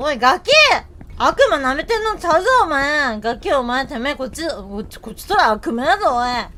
Worms speechbanks
Youllregretthat.wav